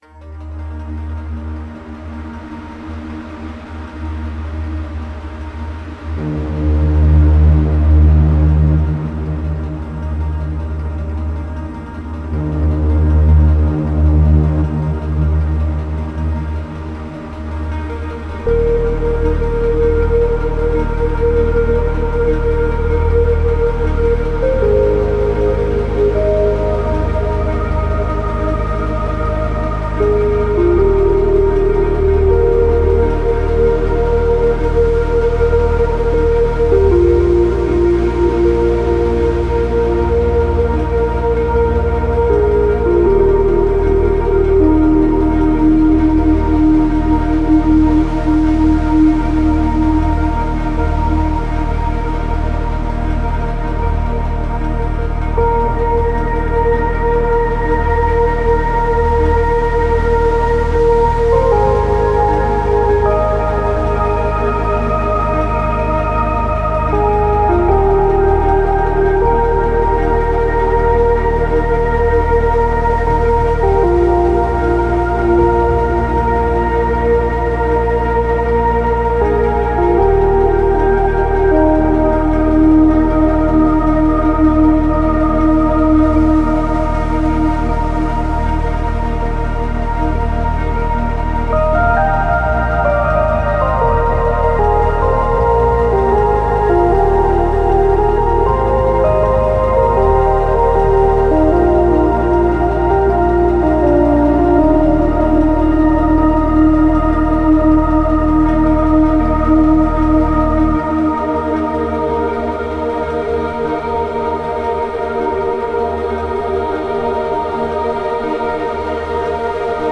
mesmerizing, pleasant, and uplifting